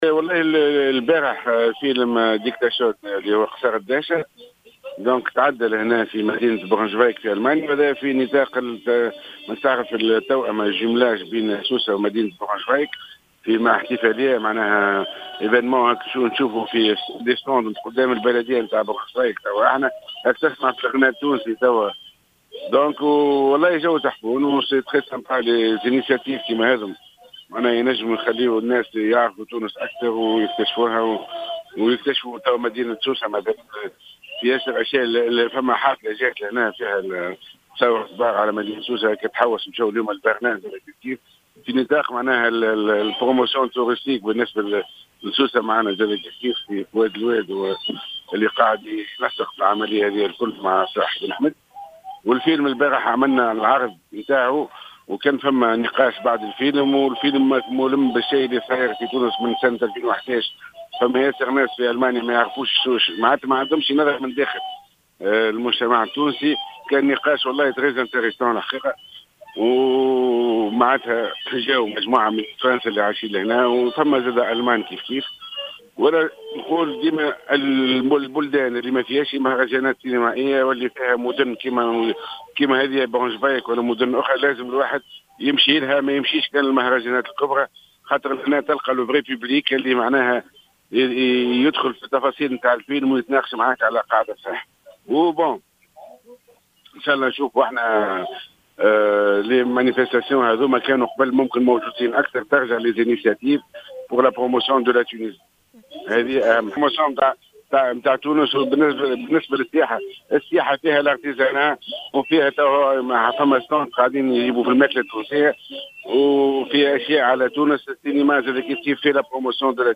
وأضاف في تصريح اليوم لـ"الجوهرة أف أم" أنها كانت بادرة، تنوعت فيها الأنشطة الثقافية والسياحية والفنية وواكبها عدد من التونسيين المقيمين في ألمانيا وكذلك الألمانيين.